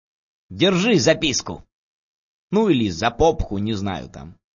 /32kbps) Описание: Прикольный звук на смс.